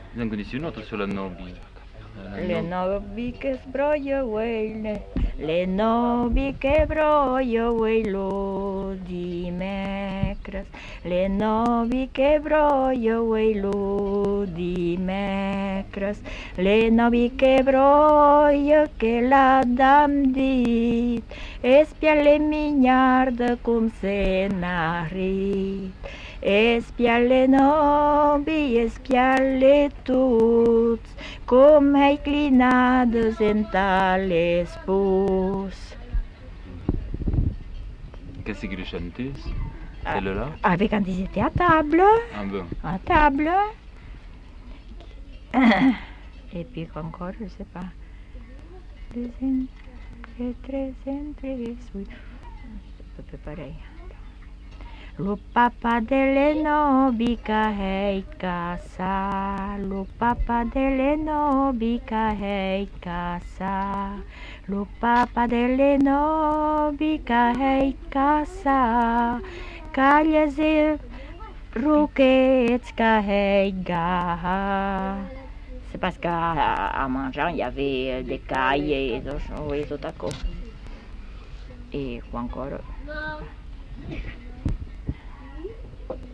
Lieu : Labrit
Genre : chant
Effectif : 1
Type de voix : voix de femme
Production du son : chanté